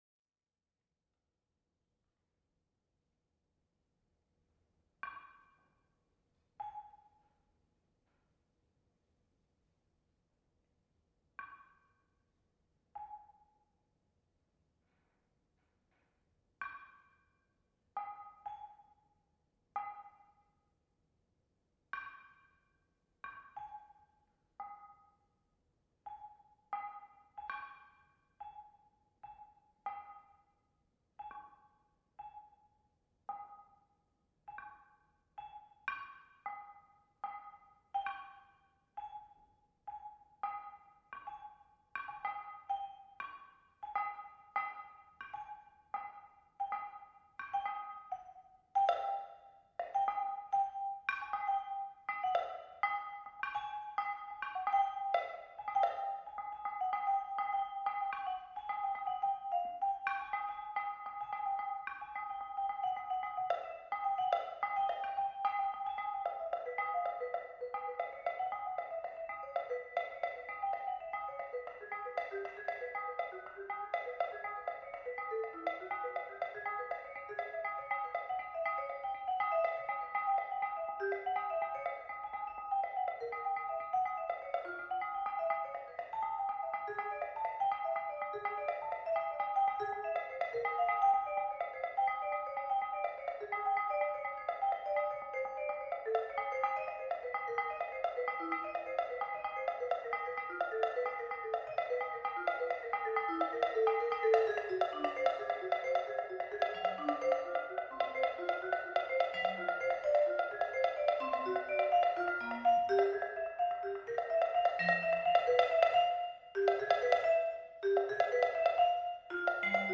Multiple Percussion (small set-up)